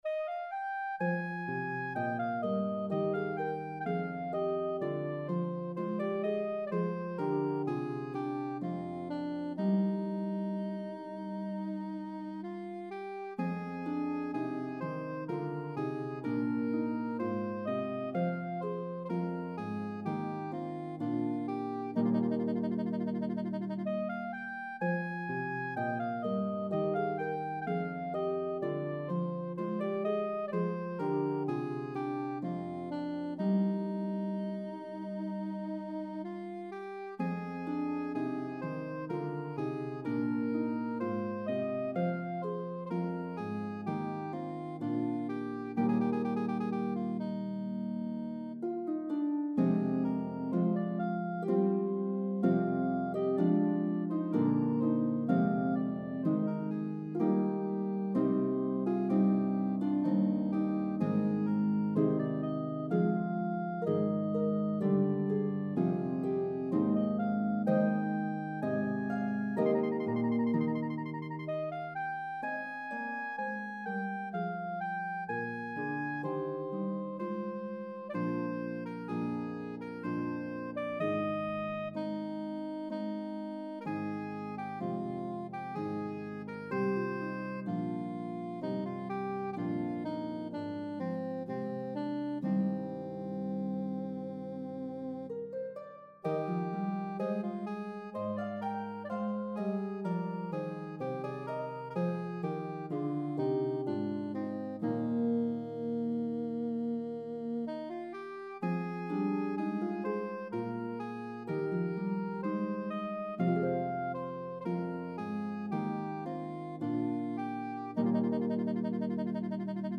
Italian Baroque style
slow air